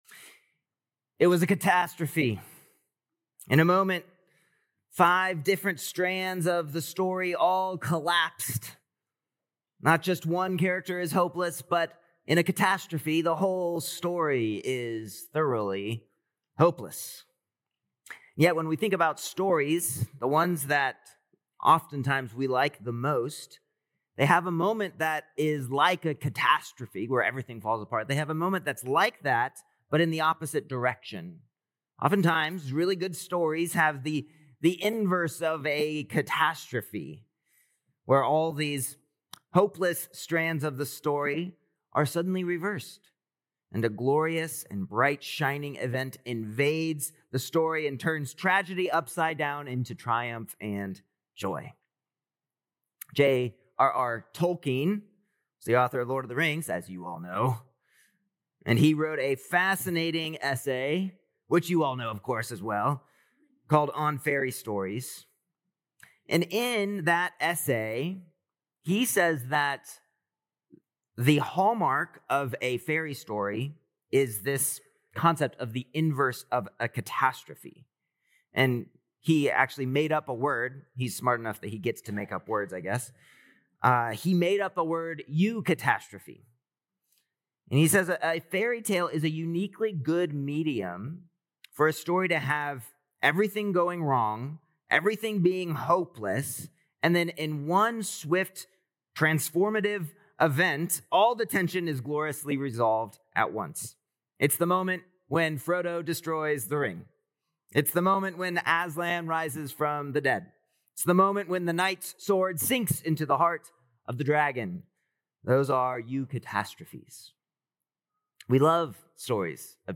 Apr 5th Sermon | Matthew 28:1-10